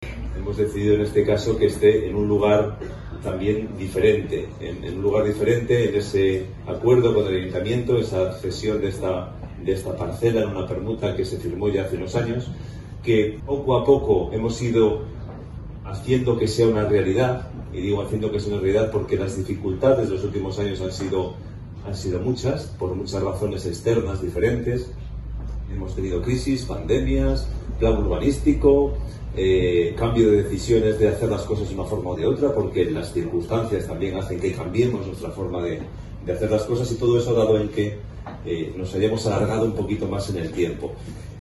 El pasado 3 de octubre se produjo el acto de colocación de la primera piedra de un nuevo gran edificio que se convertirá en el mayor centro de atención a la discapacidad de toda la región.